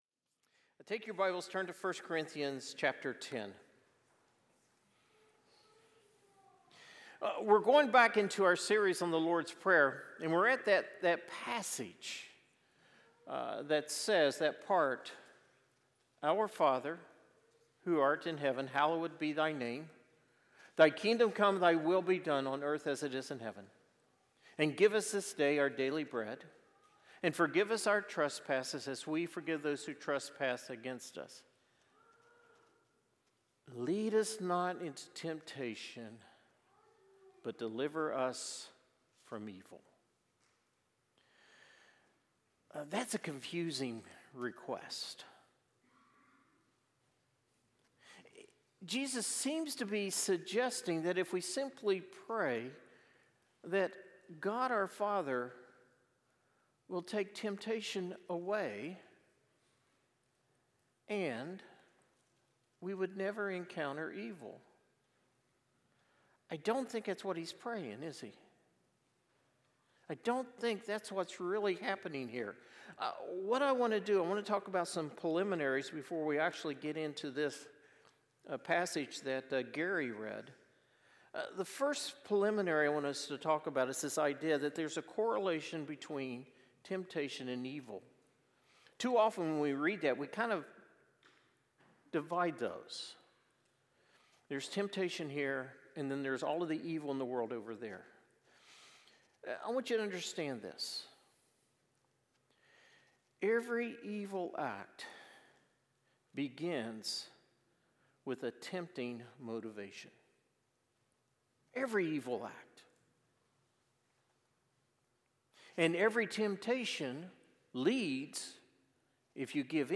Sermons | Salt Creek Baptist Church